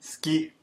The pronunciation of this, you’ll notice, sounds more like “ski” than “suki.” The “u” sound is deemphasized, almost as though it doesn’t exist (if you listen carefully, though, it’s still there a little).